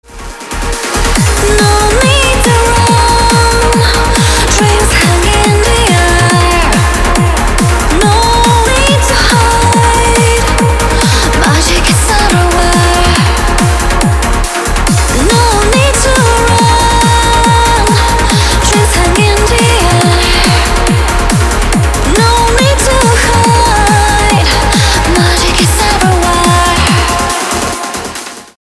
• Качество: 320, Stereo
громкие
красивые
женский вокал
dance
электронная музыка
progressive trance